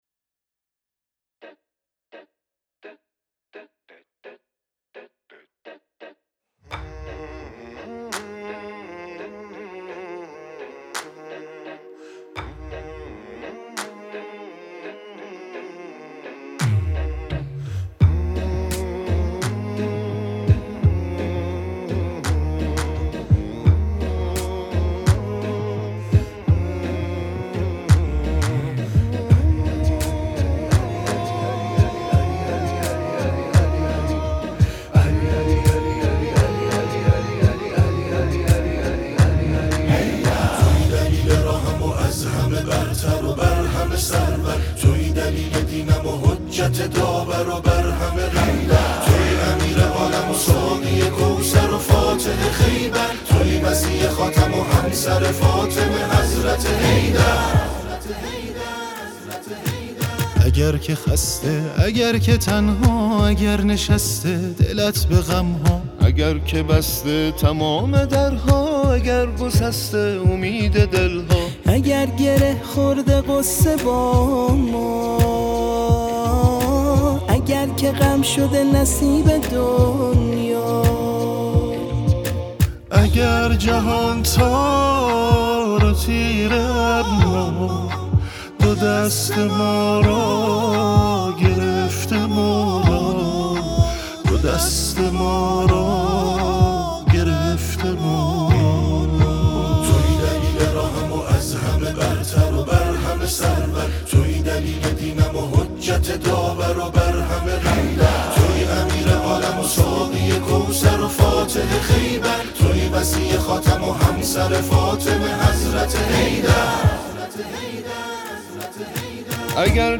تک آوا
شاد